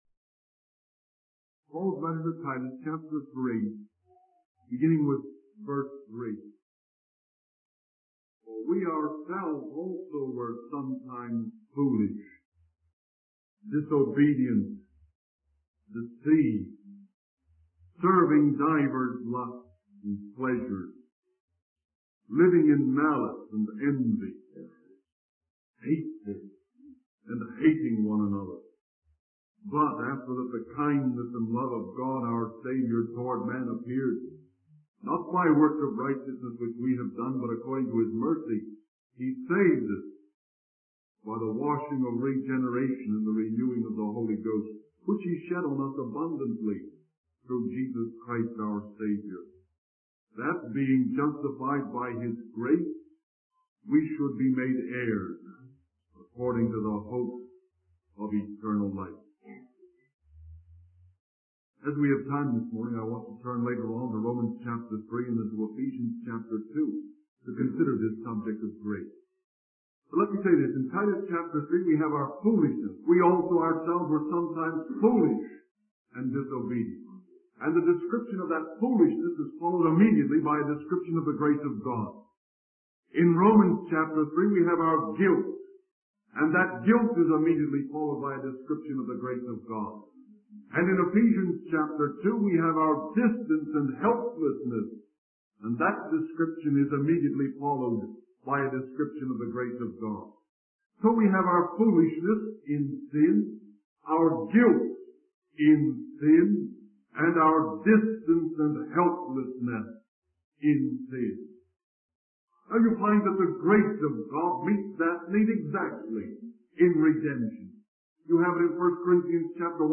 In this sermon, the preacher focuses on the concept of grace as described in the book of Romans. He emphasizes that God declares us righteous not based on our works, but on the basis of His grace. The preacher highlights that the grace of God is composed of three elements: the kindness of God, the love toward man of God, and the mercy of God.